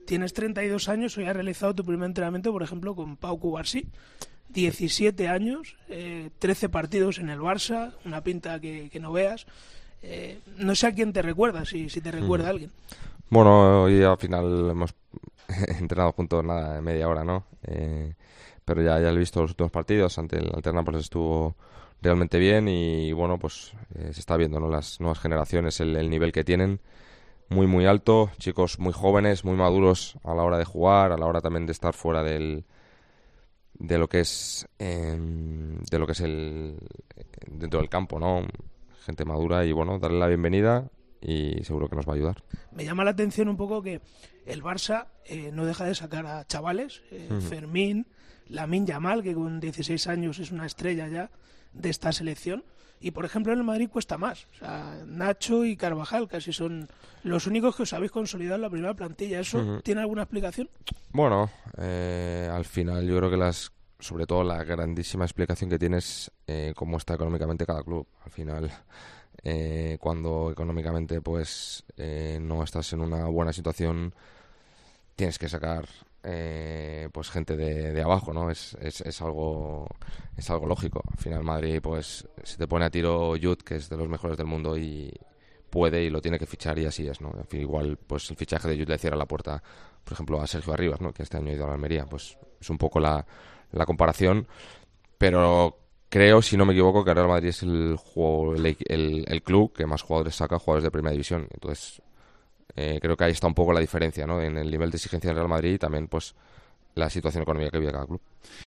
Dani Carvajal hablando en El Partidazo de COPE